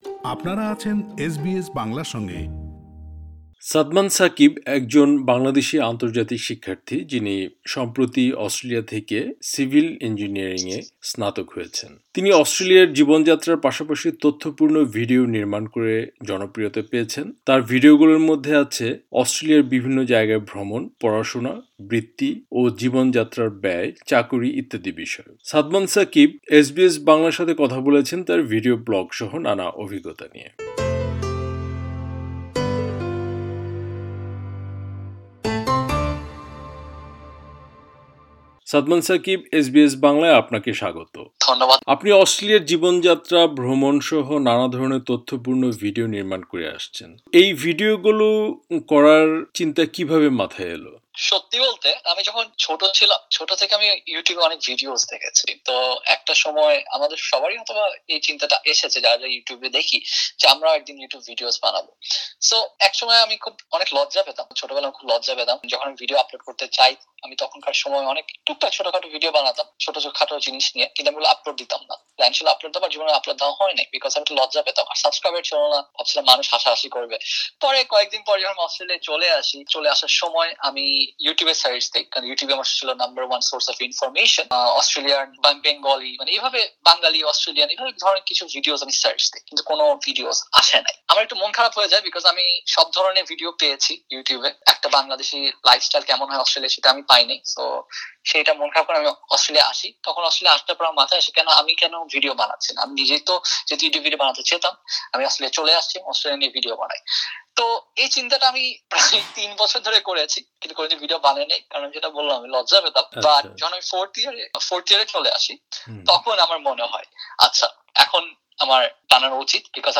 তিনি এসবিএস বাংলার সাথে কথা বলেছেন তার ভিডিও ব্লগসহ নানা অভিজ্ঞতা নিয়ে।